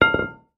Звуки гантелей
Гантель с регулируемым весом лежит на полу